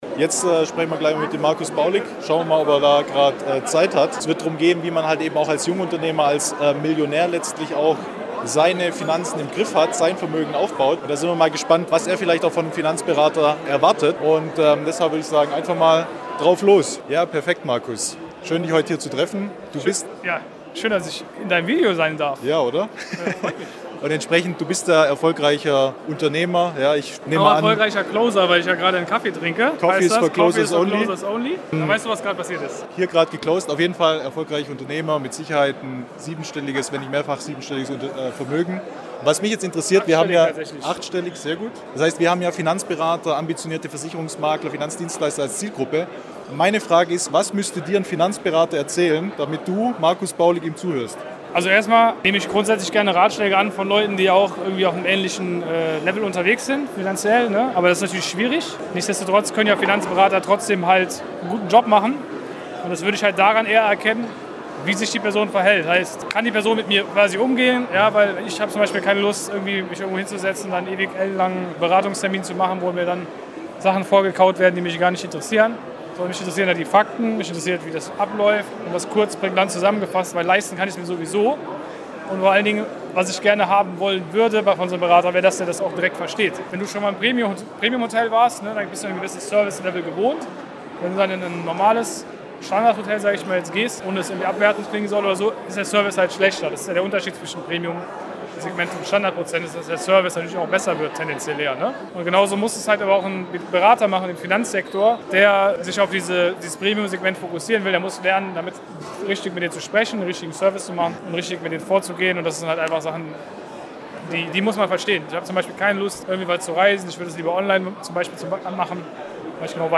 Exklusives Interview für ambitionierte Finanzdienstleister